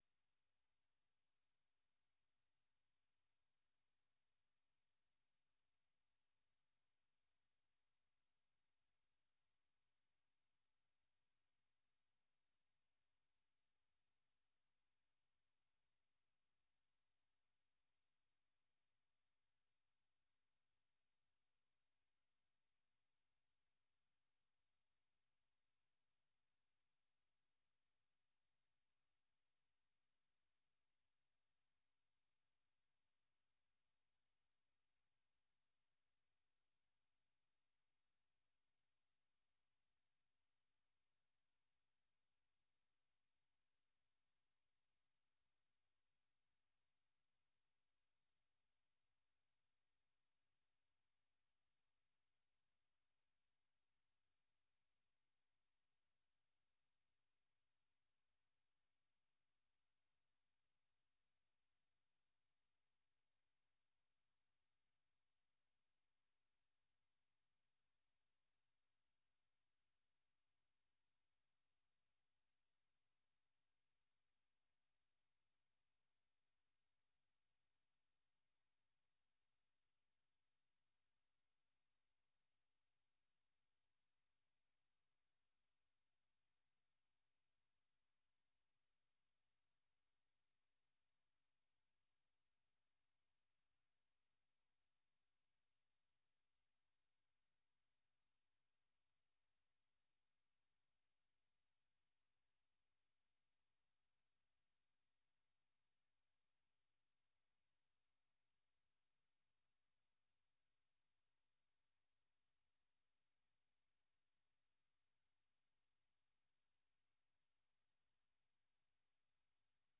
د نن او وضعیت په خپرونه کې د افغانستان د ټولنیز او فرهنګي وضعیت ارزونه ددې خپرونې له میلمنو څخه اورئ. دغه خپرونه هره شپه د ٩:۳۰ تر ۱۰:۰۰ پورې په ژوندۍ بڼه ستاسې غږ د اشنا رادیو د څپو او د امریکا غږ د سپوږمکۍ او ډیجیټلي خپرونو له لارې خپروي.